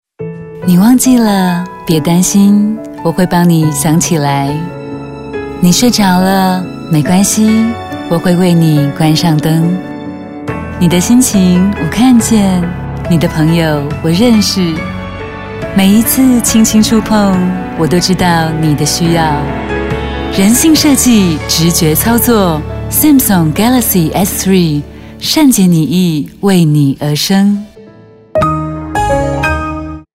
國語配音 女性配音員
質感、溫柔
質感、專業
• 廣告與品牌配音